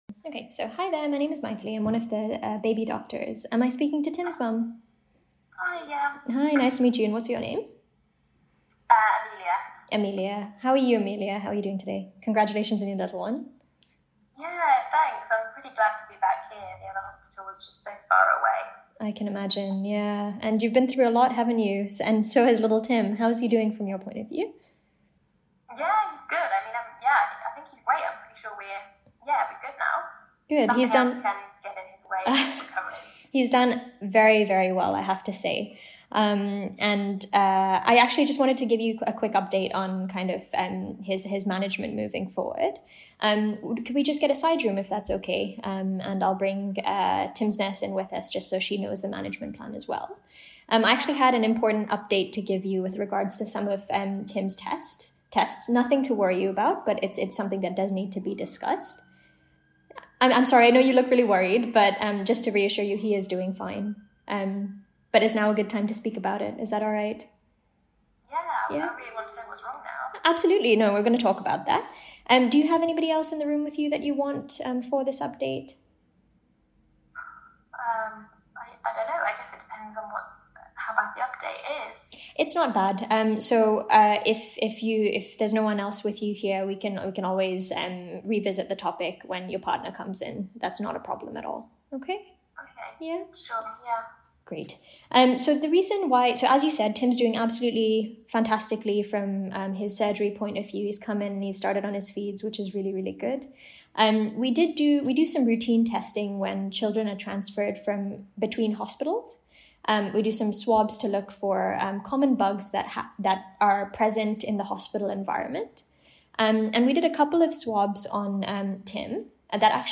80 clinical scenarios, supported by audio recordings of example answers, to help you prepare!
Role: You are the Specialist Neonatal Registrar